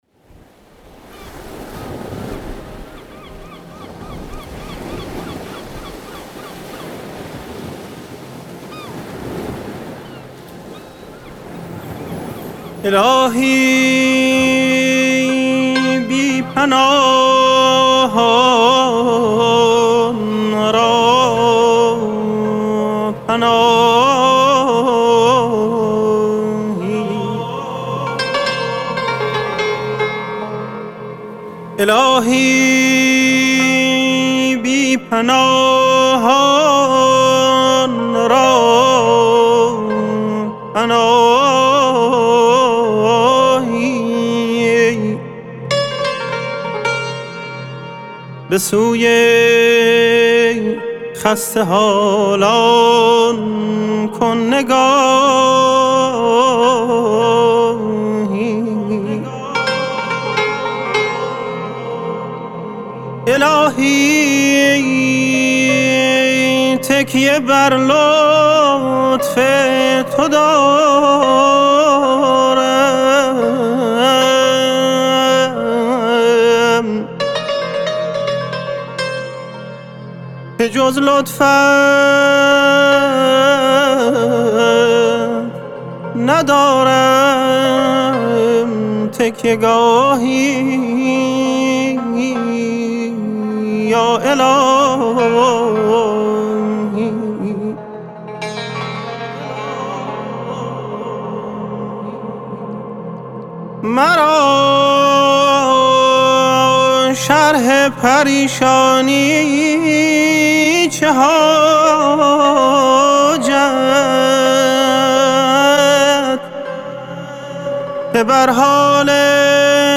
مناجات‌خوانی